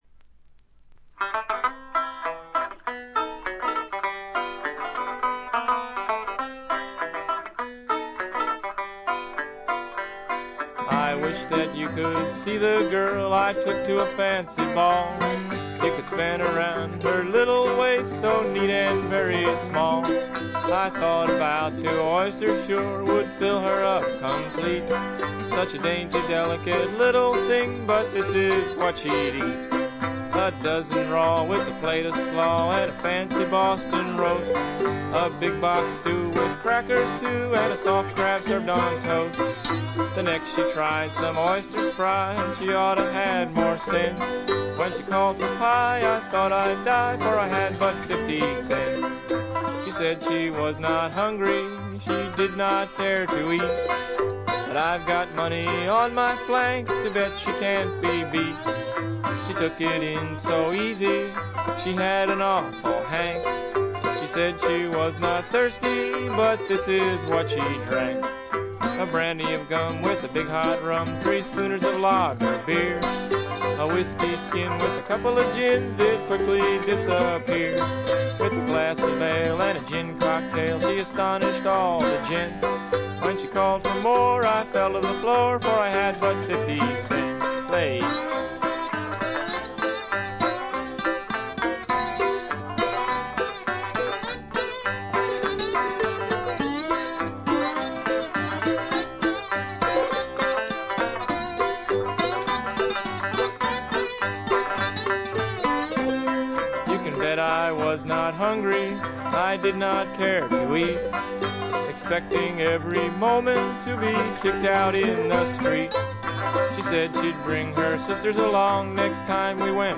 ところどころでチリプチ少々、散発的なプツ音少々。
戦前のブルース、ジャズ、ラグ、ストリングバンドといった世界をどっぷりと聴かせてくれます。
文字通りのチープで素人くささが残る演奏が愛すべき作品です。
試聴曲は現品からの取り込み音源です。